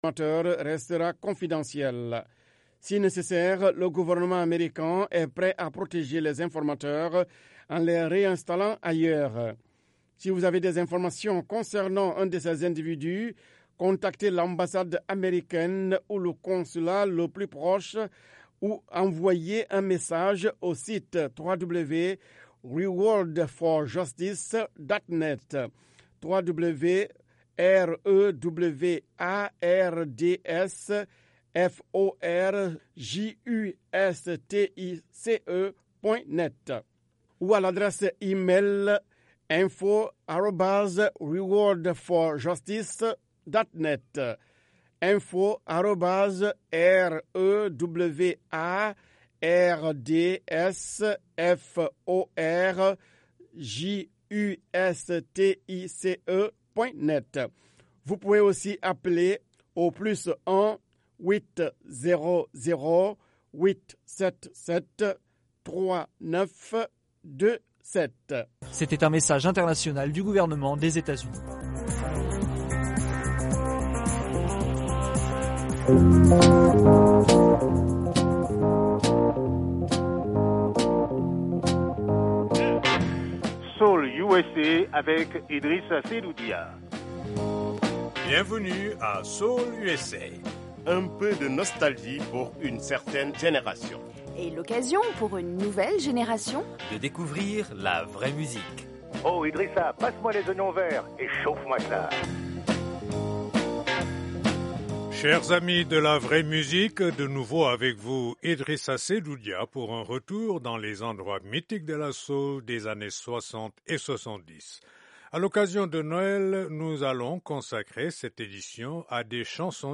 Les bons vieux tubes des années 60 et 70.